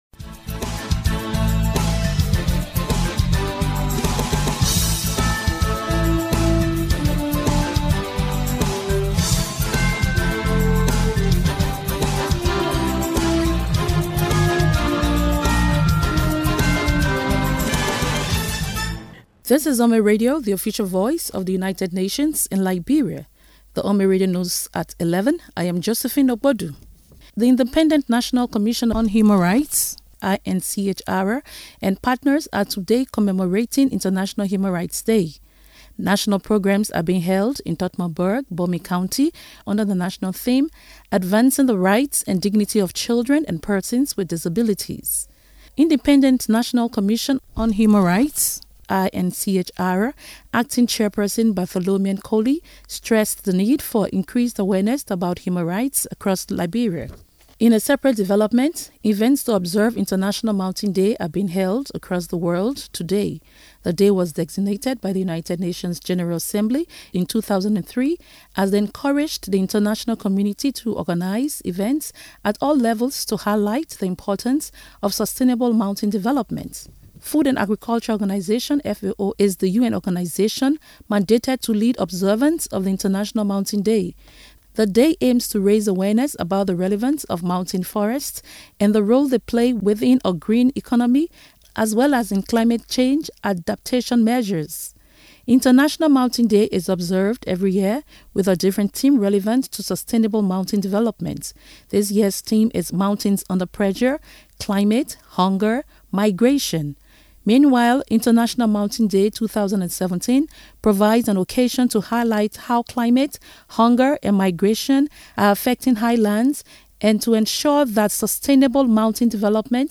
UNMIL radio news at 11 AM on Monday 11 December 2017